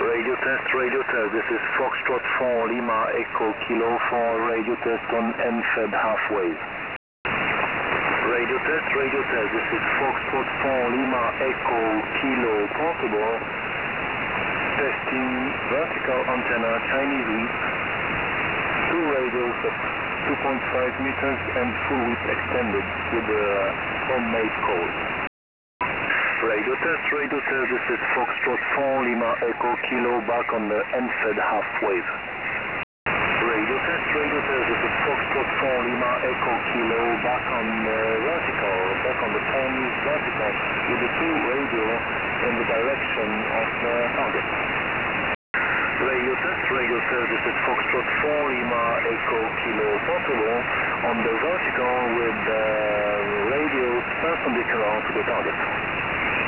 Audio comparison on 40m, EFHW (20m long, 6m mast) vs vertical whip + coil. WebSDR is located at 250 km.
In the audio sample you can hear in order: EFHW broadside to target - Vertical with radials in the axis of target - EFHW again - Vertical with radials in the axis of target - Vertical with radial perpendicular of target.
All audio sample are recorded with only a few seconds interval.
The EFHW is clearly wining on this one, beating the (high today) noise floor easily.
efhw_vs_vertical_whip_40m.wav